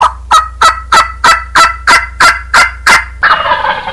Truthahne Klingelton Kostenlos
Kategorien Tierstimmen